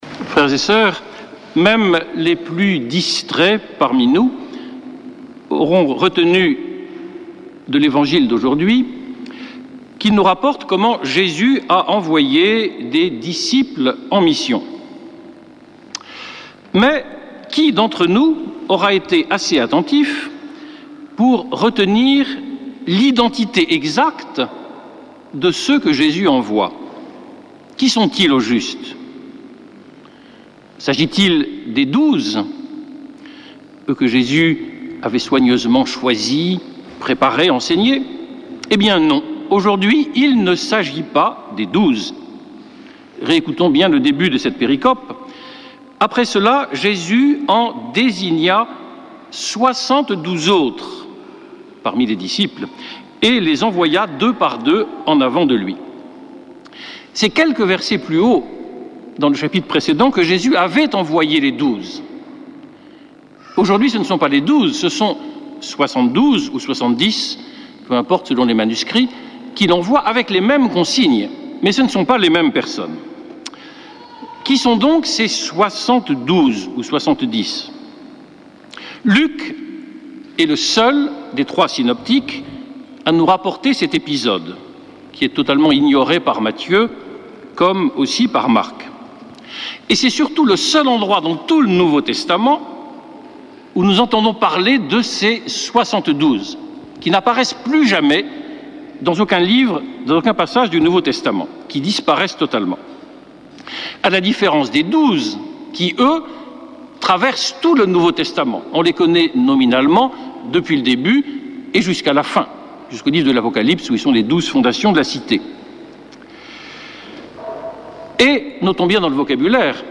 Messe depuis le couvent des Dominicains de Toulouse